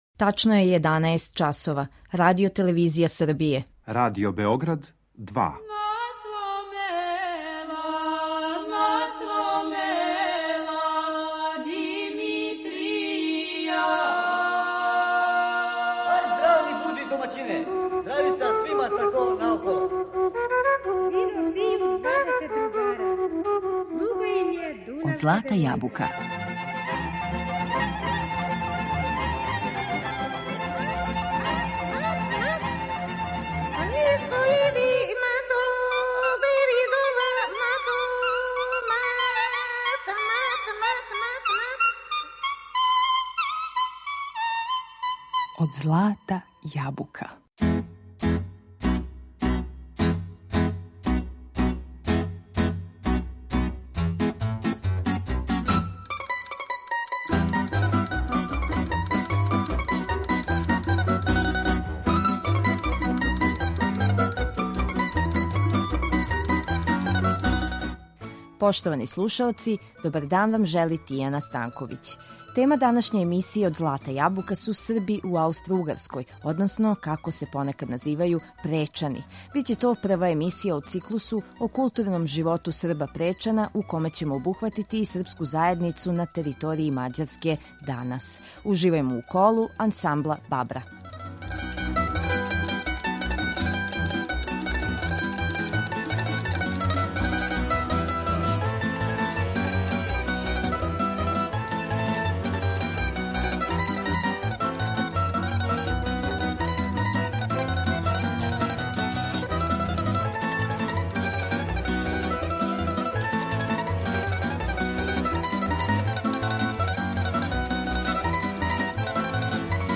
На репертоару су ансамбли „Вујичић", „Шендерге", „Бабра", „Сербоплов" и војвођански гајдаши.